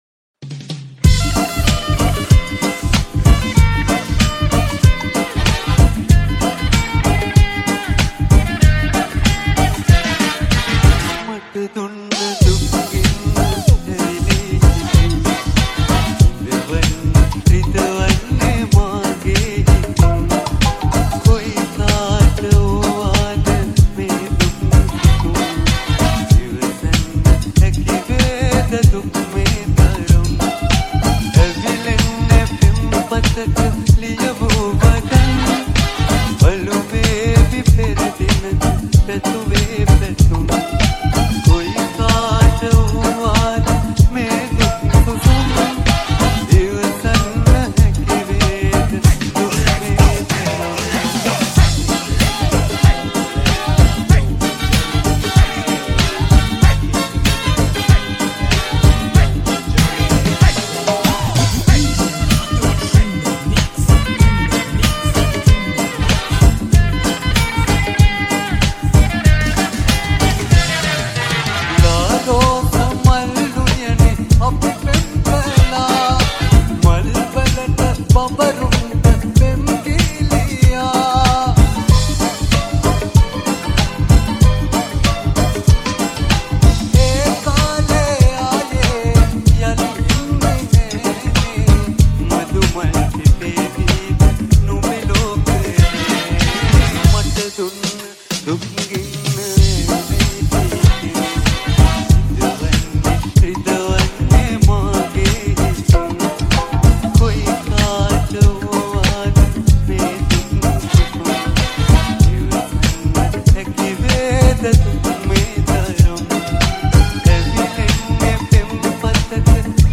Reggae Remix